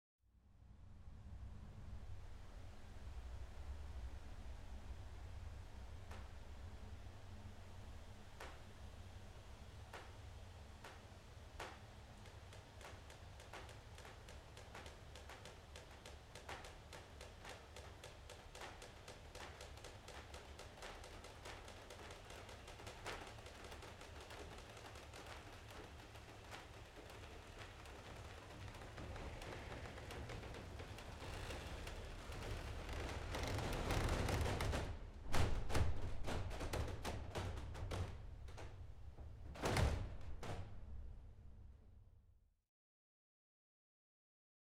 Klosterorgel Engelberg: The last “sighs” of the organ bellows during shutdown
Orgelgebläse beim Ausschalten